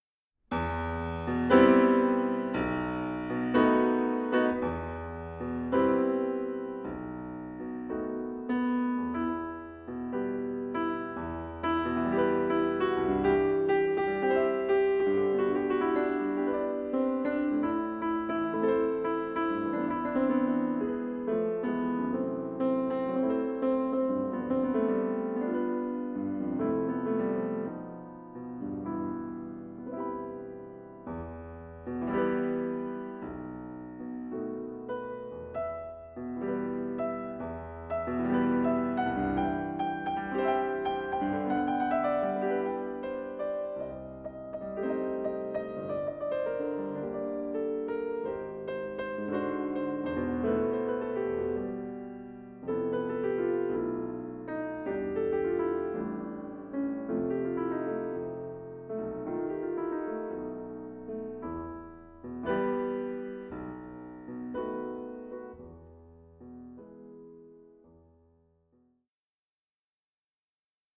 these solo piano works, both charming and imposing